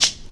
JayDeeJazzHat.wav